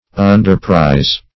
Underprize \Un`der*prize"\